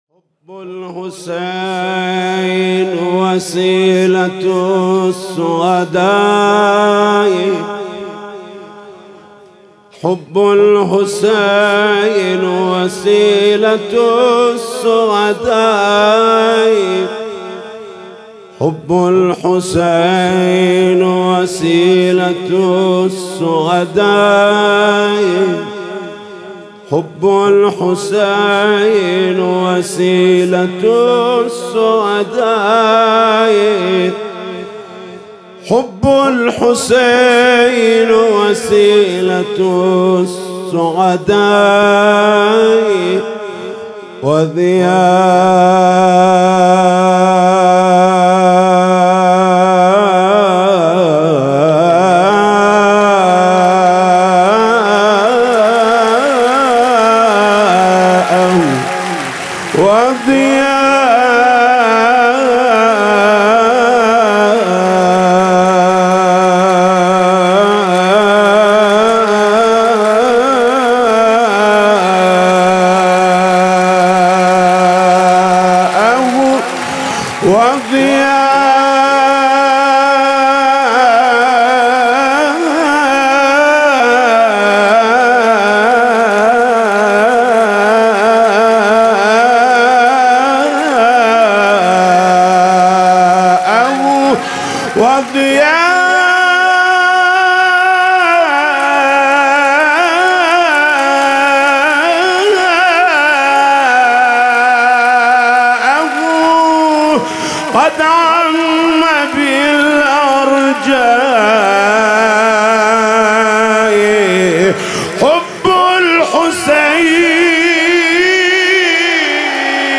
ابتهال: حب الحسين وسيلة السعداء